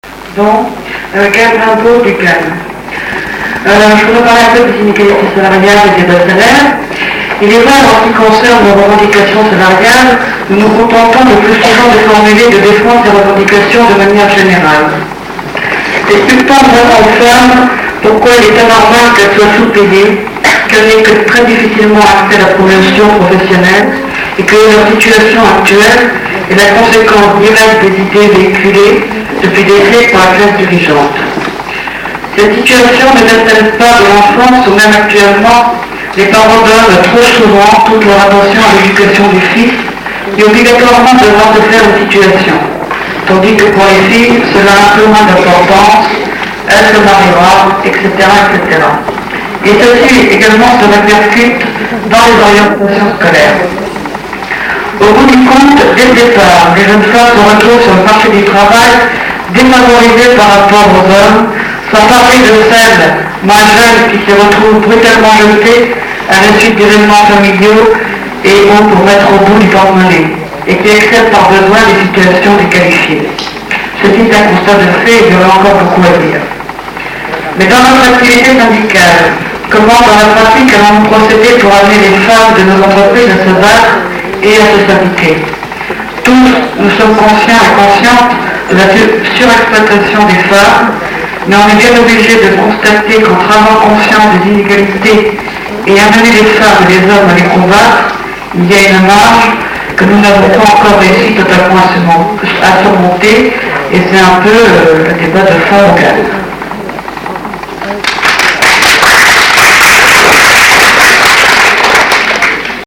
Violences faites aux femmes, discriminations, inégalités de salaires, métiers d’appoint, double journée de travail. Regard sur les femmes travailleuses avec quelques extraits d’archives sonores inédites. En effet, je crois bien avoir été la seule, en 1977, à avoir enregistré les journées des travaux de la 6ème Conférence Femmes de la C.G.T. J’y ai moi même participé comme déléguée des magasins populaires et élue de la Commission femmes CGT du commerce parisien car j’ai travaillé douze ans comme vendeuse, notamment au Prisunic des Champs- Elysées, dès 1971.
Malheureusement, je n’ai pas pu sauver tout ce que j’avais enregistré en 1976 et 1977, et deux cassettes, parmi celles que j’ai retrouvées, ont été, en partie, démagnétisées.